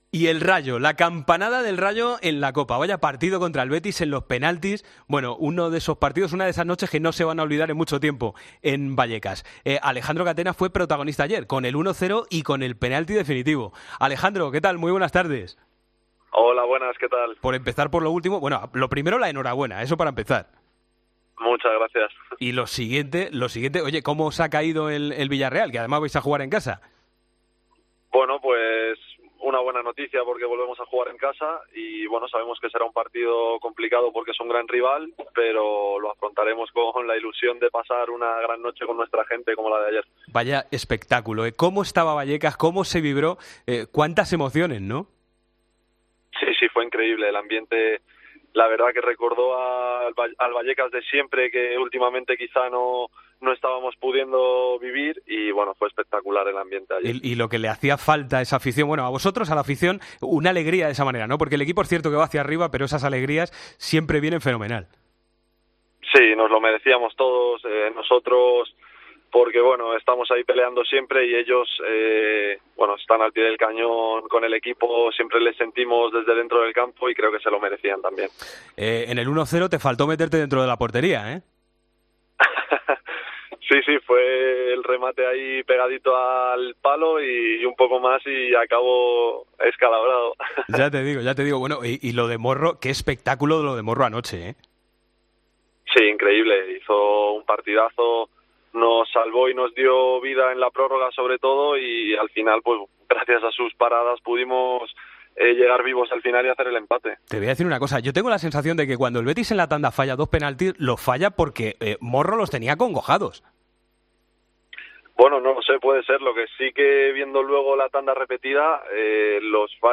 Hablamos con el central del Rayo tras la victoria ante el Betis y el emparejamiento con el Villarreal.